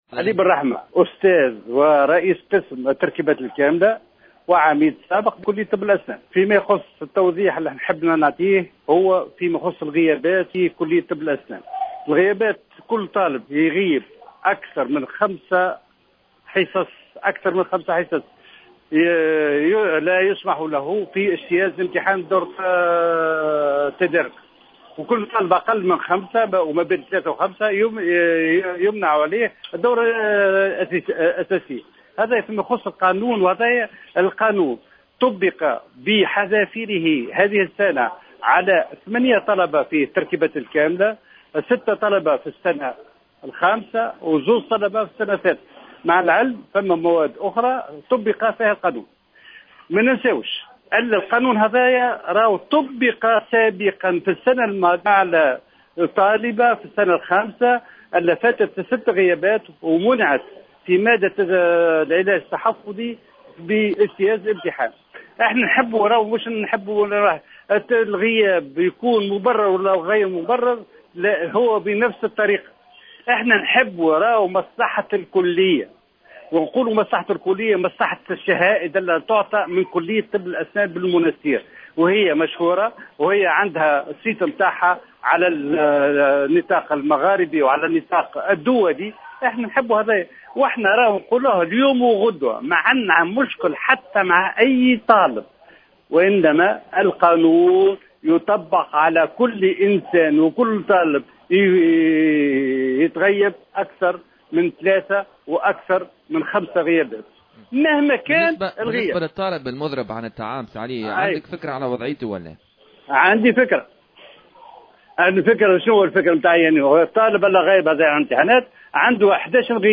في تصريح للجوهرة أف أم